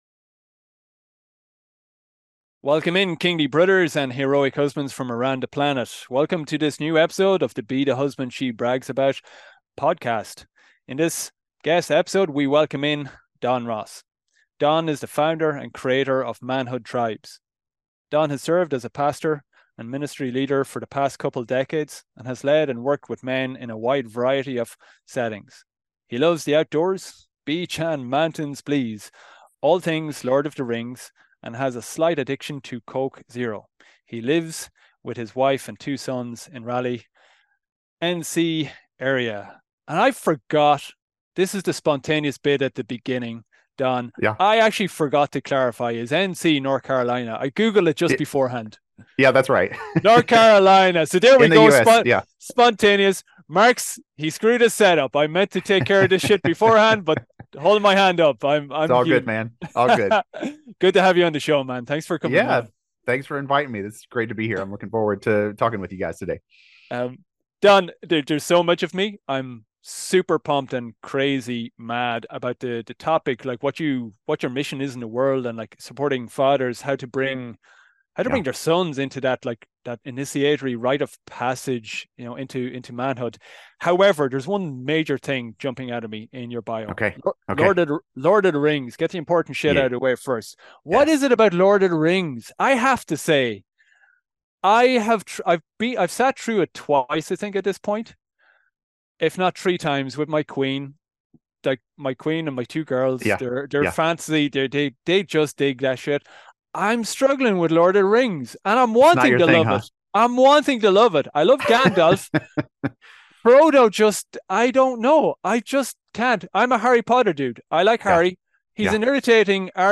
This is a kick-ass conversation